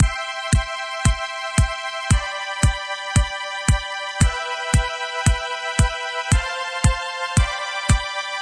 トラック１にキック・ドラム、トラック２にシンセ音を入力しました。普通に鳴っているだけです。
Side-chain-test-0.mp3